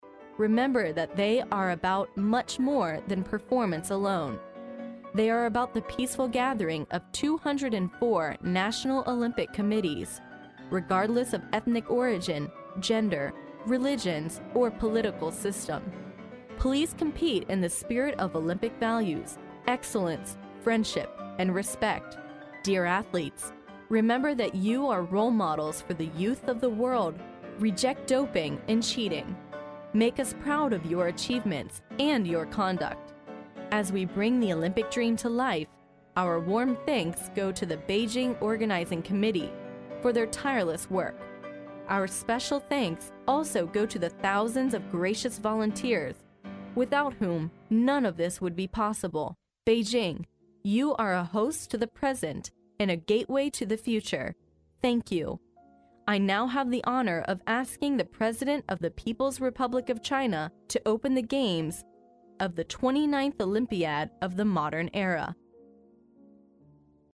历史英雄名人演讲 第49期:北京奥运开幕式致辞(2) 听力文件下载—在线英语听力室